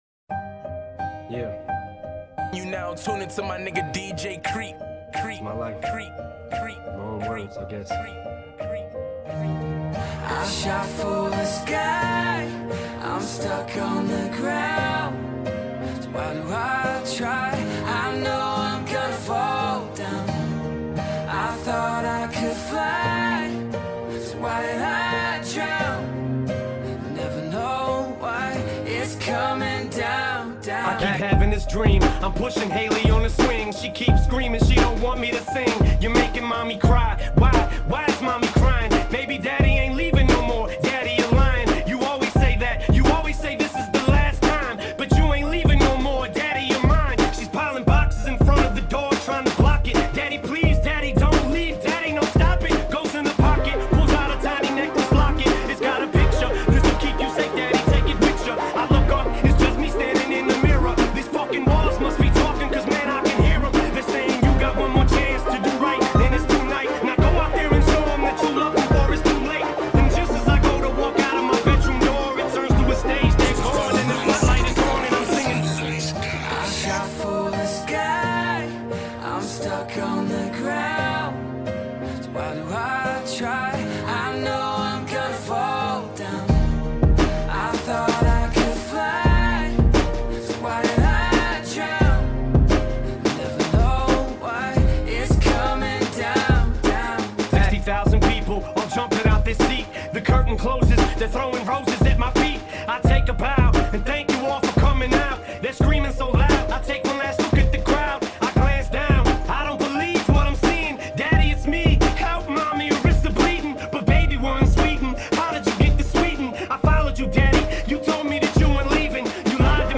Категория: Зарубежный рэп